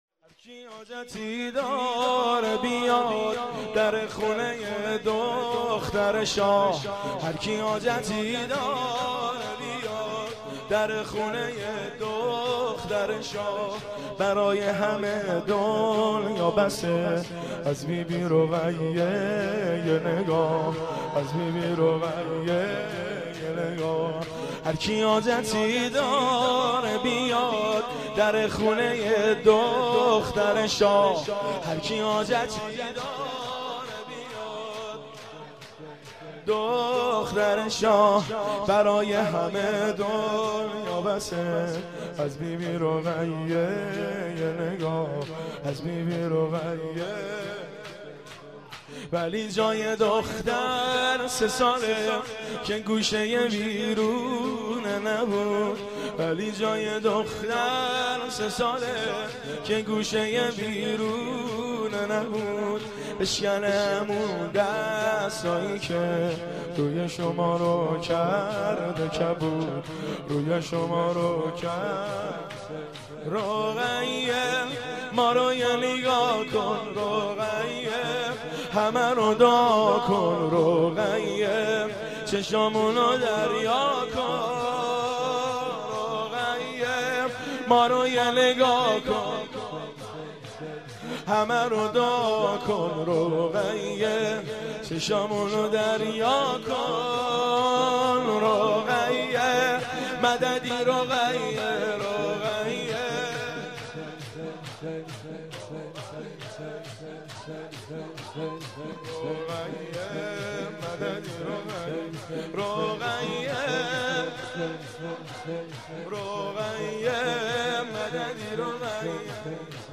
مهدیه تهرانپارس
شور